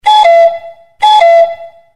ハト時計（2時）